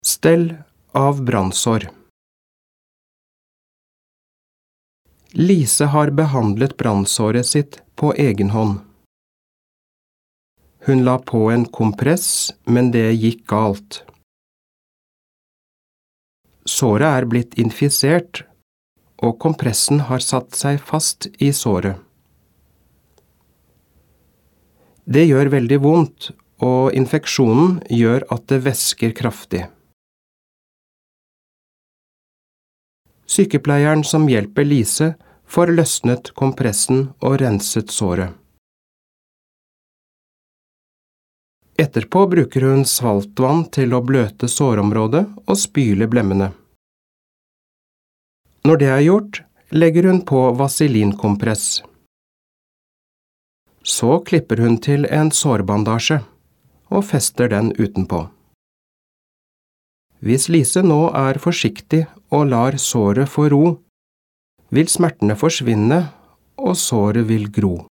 Diktat leksjon 4
• Tredje gang leses teksten sammenhengende, og du skal kontrollere det du har skrevet.